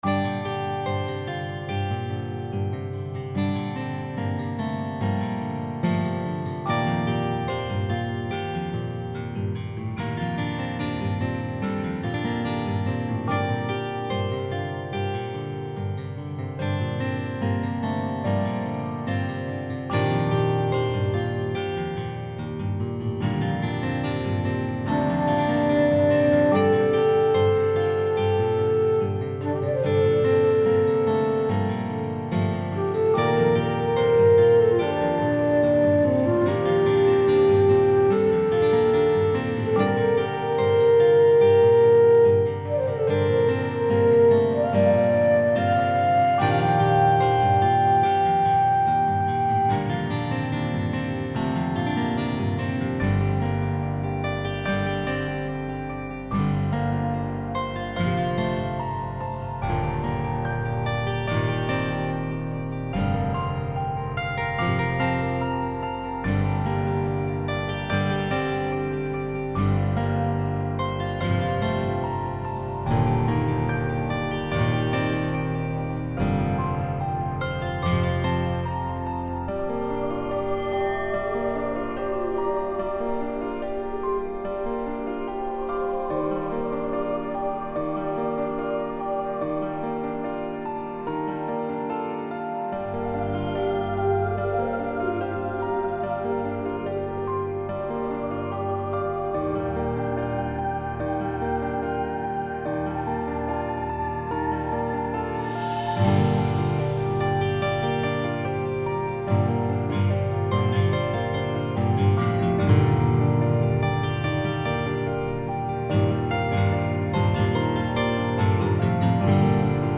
This is a calm, magical forest theme that becomes epic and emotional towards the end..
orchestra strings percussion celtic guitar choir woodwinds noire piano calm magical epic emotional